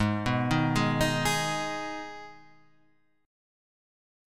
G#m Chord